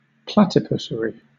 Ääntäminen
Vaihtoehtoiset kirjoitusmuodot platypussary Ääntäminen Southern England: IPA : /ˈplætɪpʊsəɹi/ Haettu sana löytyi näillä lähdekielillä: englanti Käännöksiä ei löytynyt valitulle kohdekielelle.